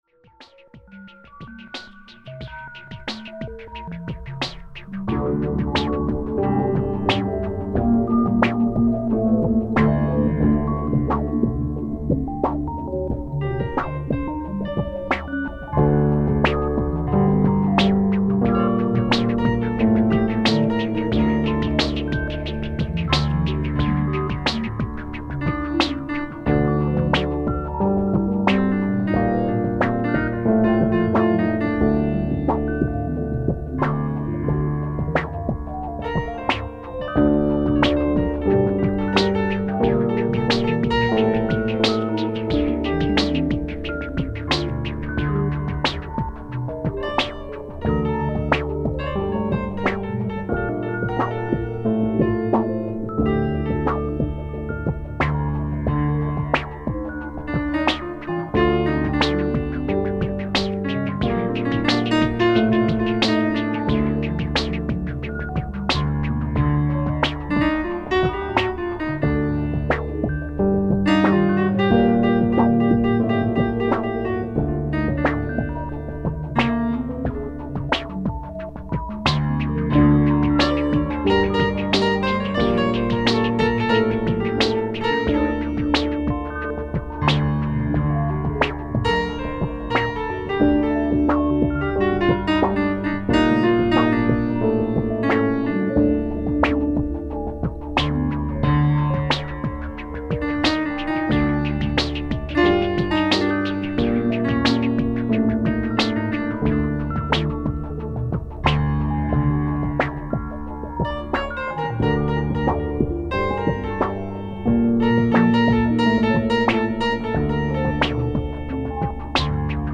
Wabernde Synthie Klänge mit frei..